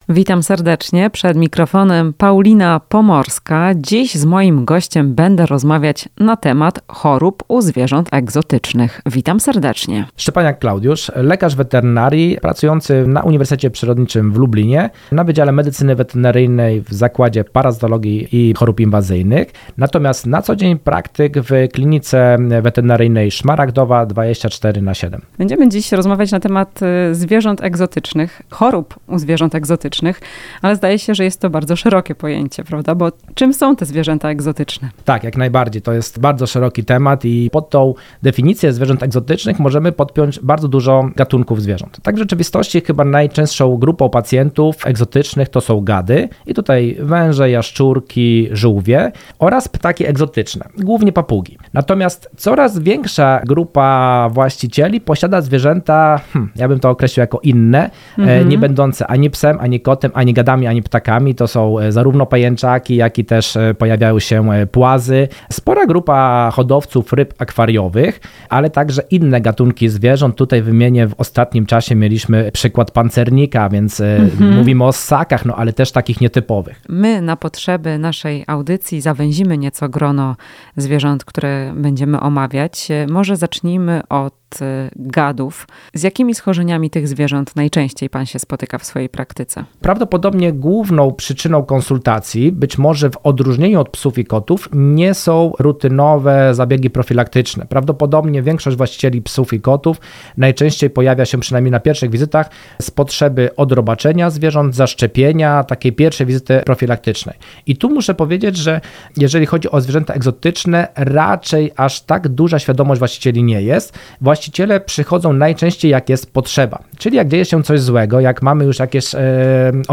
W "Chwili dla pupila" powiemy, z jakimi problemami do weterynarza zgłaszają się najczęściej opiekunowie gadów. Rozmowa z lek. wet.